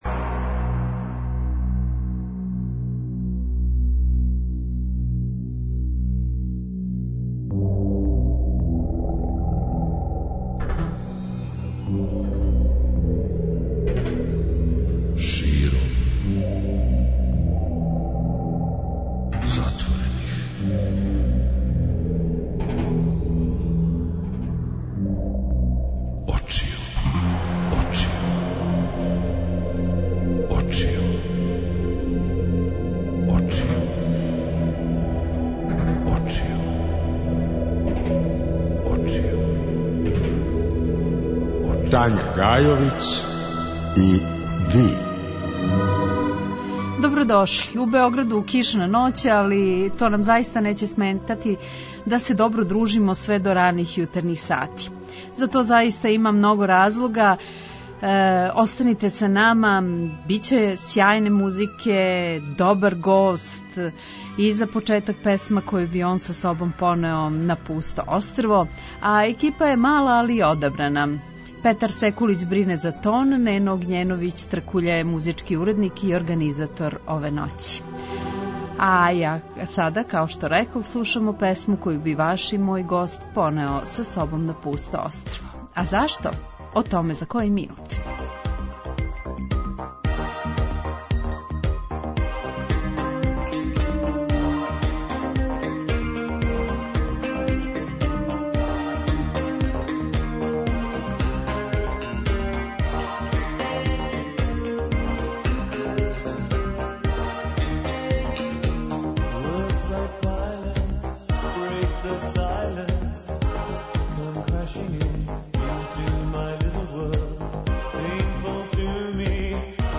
Гост: Владимир Граић, директор ПГП РТС и композитор